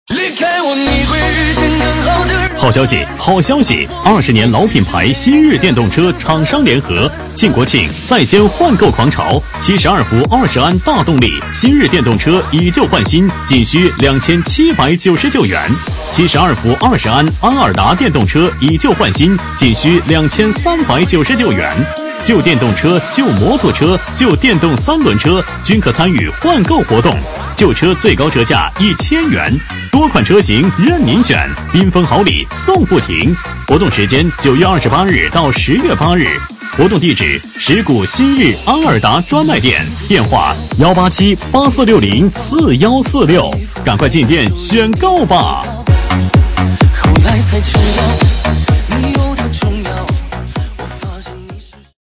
【男19号促销】新日电动车
【男19号促销】新日电动车.mp3